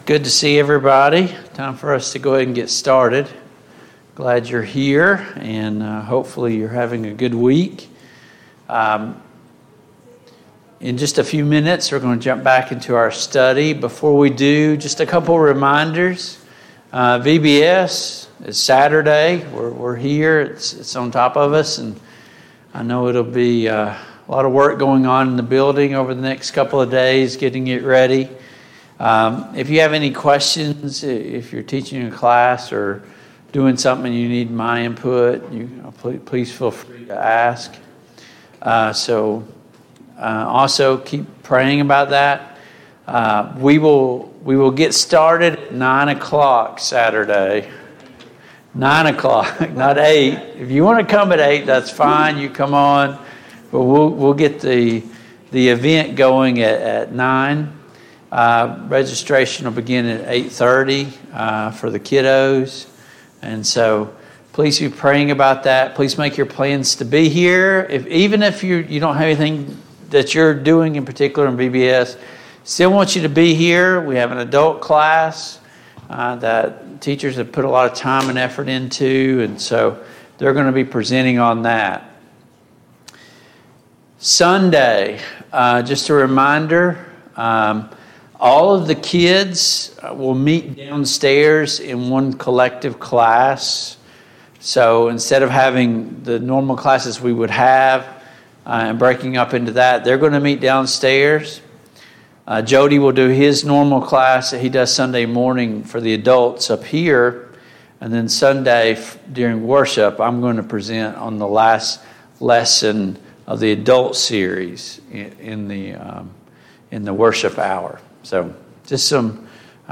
The Kings of Israel Passage: 1 Kings 13, 1 Kings 14 Service Type: Mid-Week Bible Study Download Files Notes Topics